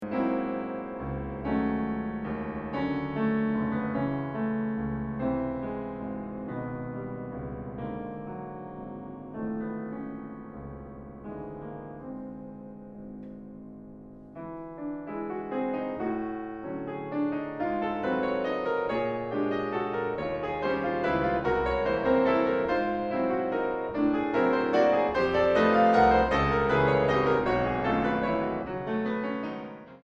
Piano Bösendorfer 290 Imperial.